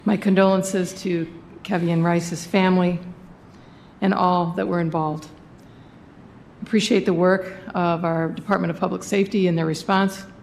City commissioners commented on the city’s third homicide of the year last night.
Vice Mayor Jeanne Hess said the community has to try harder to find alternatives to gun violence.